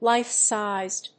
アクセントlífe‐sízed